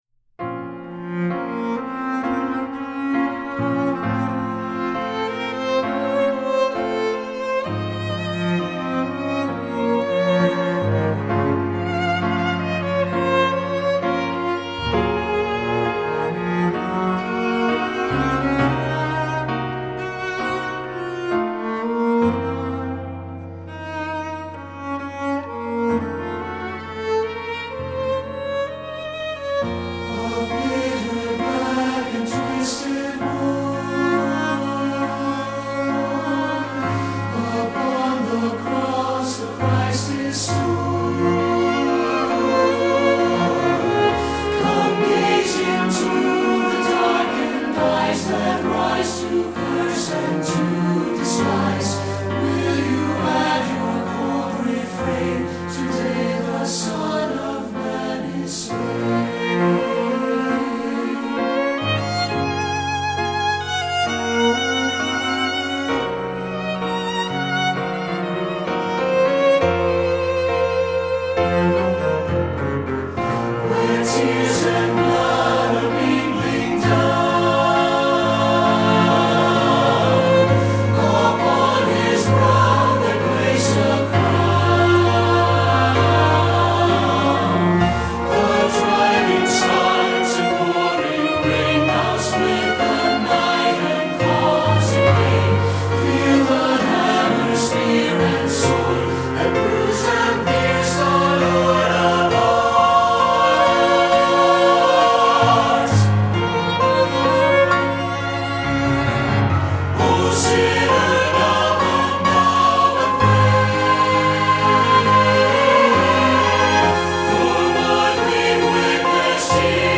Voicing: SATB, Violin and Cello